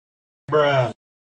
1. Мем звук BRUH оригинал бруэ